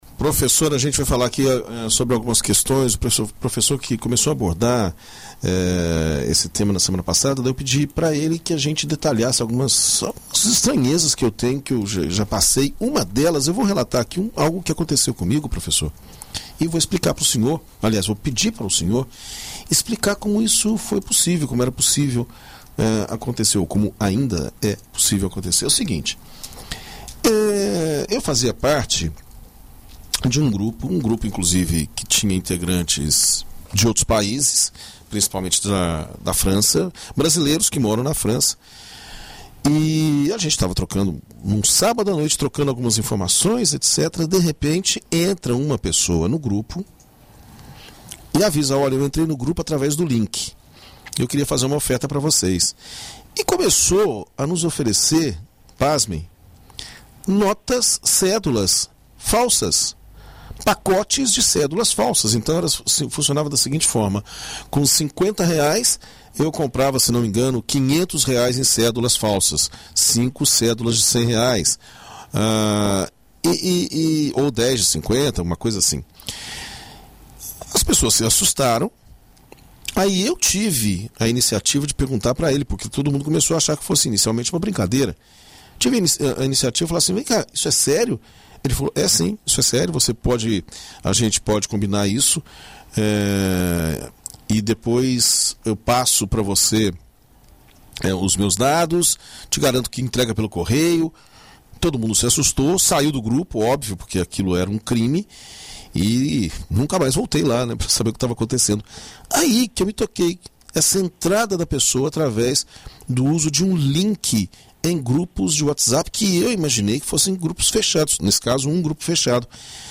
A prática, no entanto, assusta usuários, já que qualquer pessoa, conhecida ou não, pode ter acesso ao contato de todos os participantes do grupo. Na rádio BandNews FM Espírito Santo